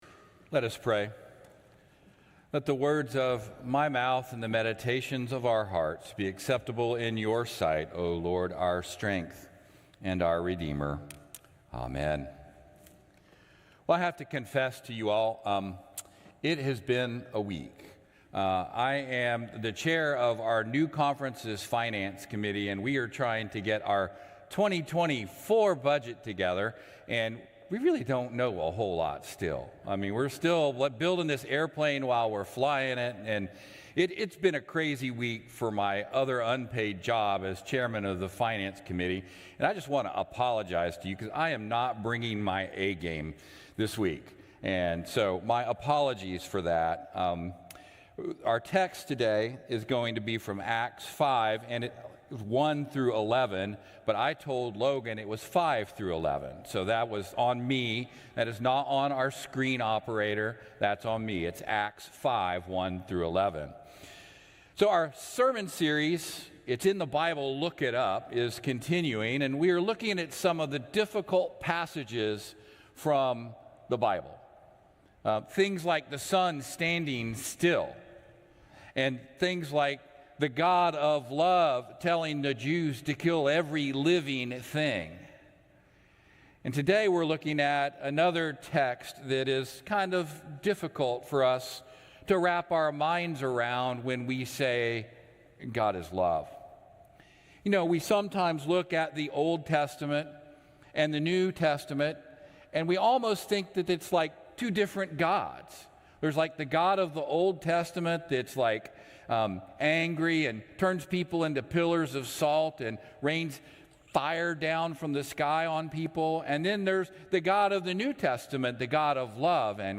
Traditional-Service-—-Jun.-18.mp3